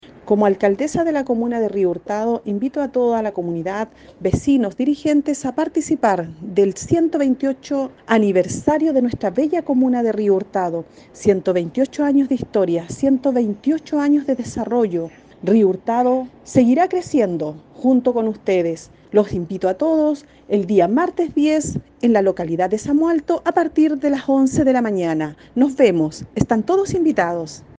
La alcaldesa Carmen Juana Olivares invita cordialmente a todas las vecinas y vecinos de Río Hurtado a participar de esta importante fecha para la comuna.